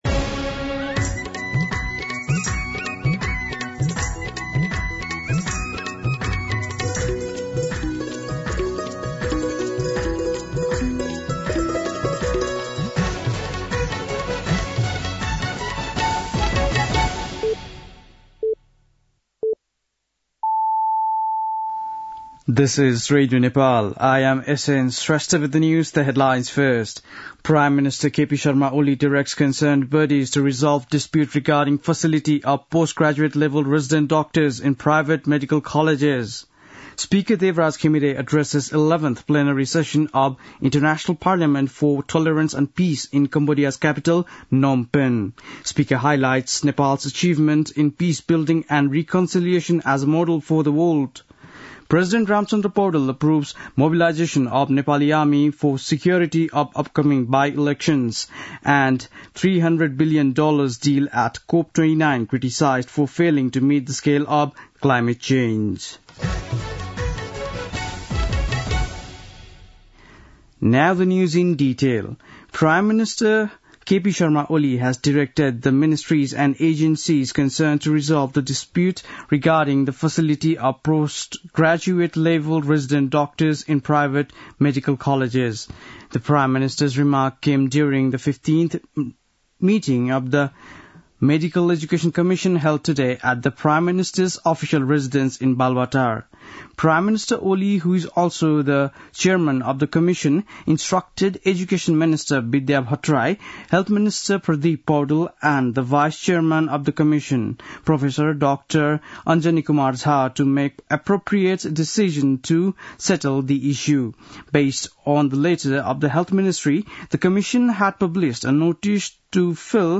बेलुकी ८ बजेको अङ्ग्रेजी समाचार : १० मंसिर , २०८१
8-PM-English-News-8-9.mp3